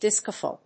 音節dis・co・phile 発音記号・読み方
/dískəfὰɪl(米国英語)/